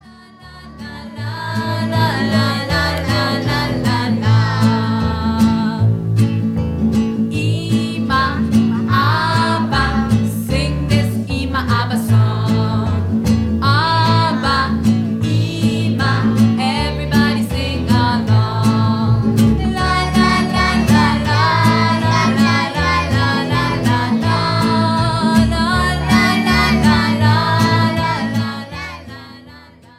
Recorded before an audience of children